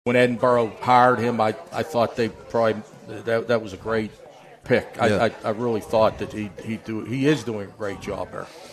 On last night’s Hawk Talk on U92 radio and Renda Digital TV